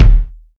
kick 28.wav